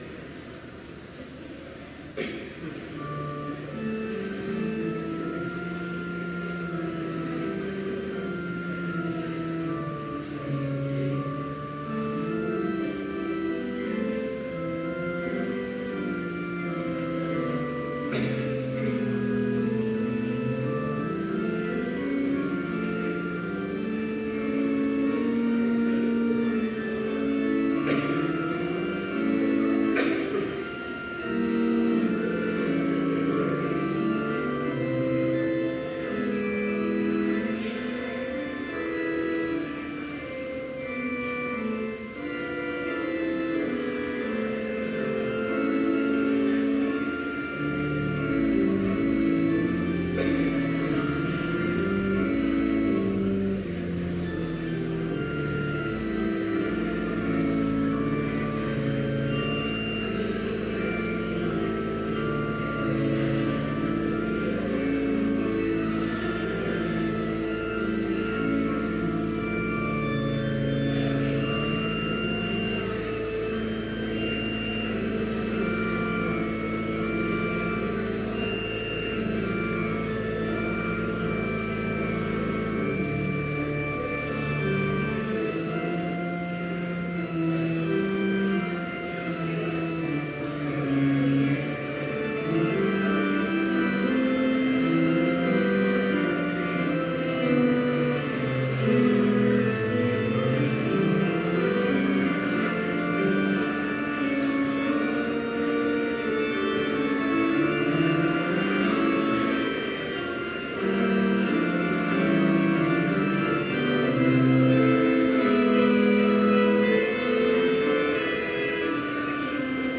Complete service audio for Chapel - October 16, 2018